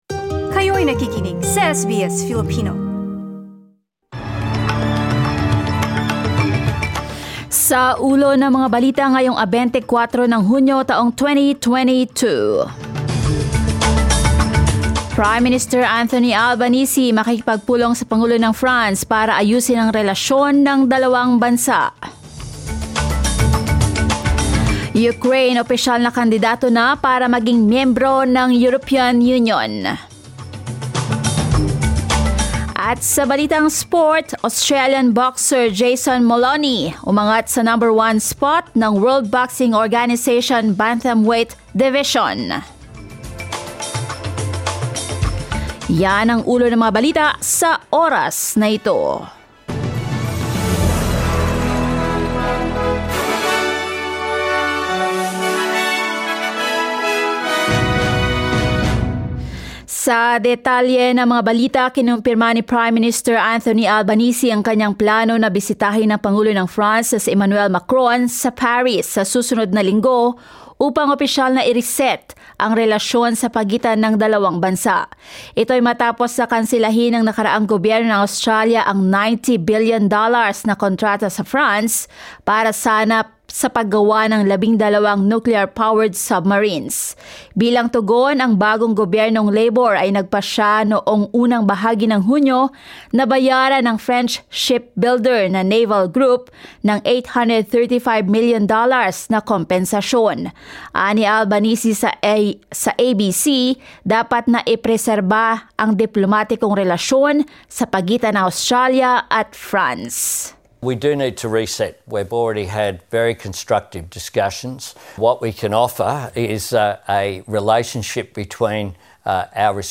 SBS News in Filipino, Friday 24 June